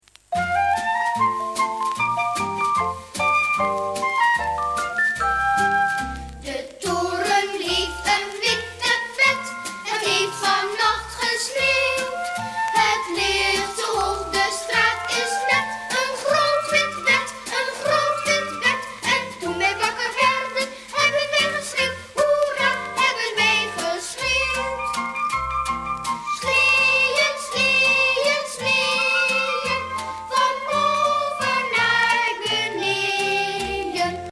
Liedjes